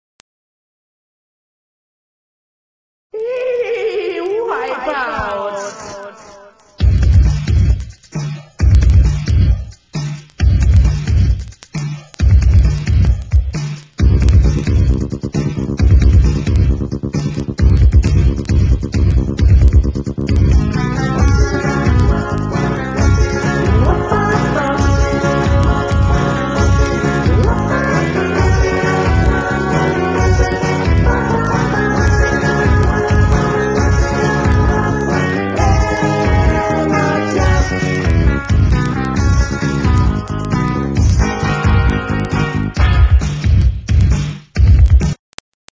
HIPHOP, RAP KARAOKE CDs